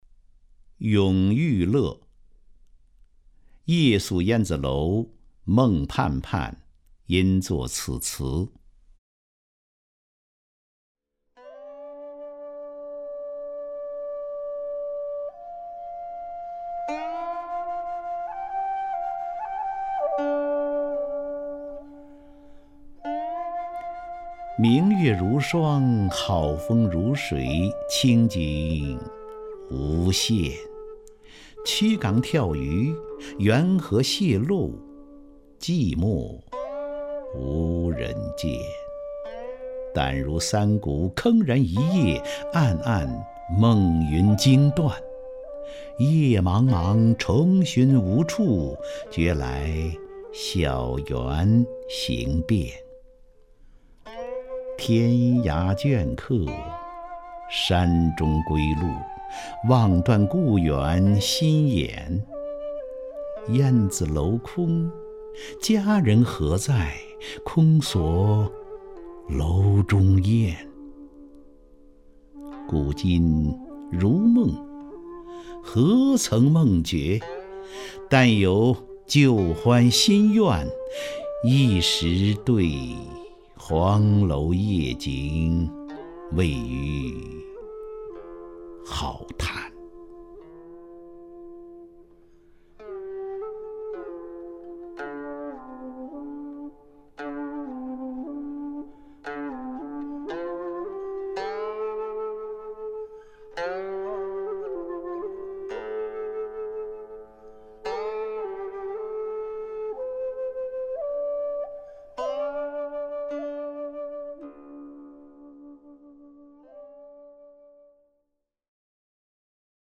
张家声朗诵：《永遇乐·明月如霜》(（北宋）苏轼)
名家朗诵欣赏 张家声 目录